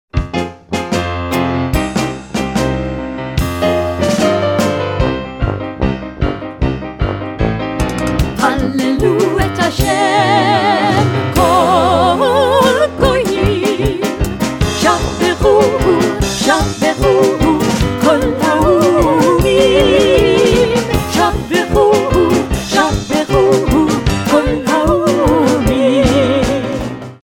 a song of praise with a driving, syncopated beat